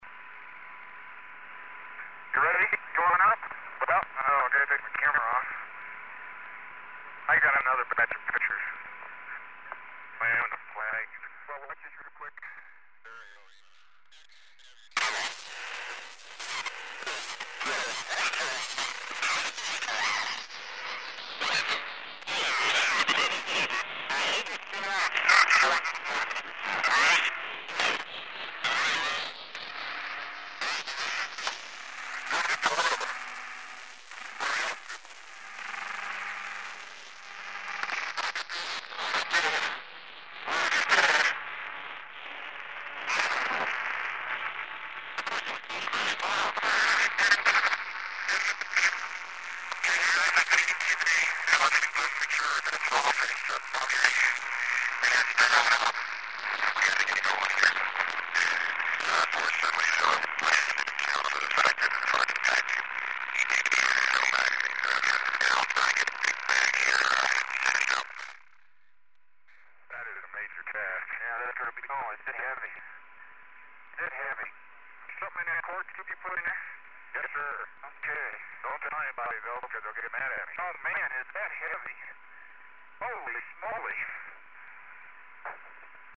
turn your volume down - some of these are loud - - no other effects or eq used in making these samples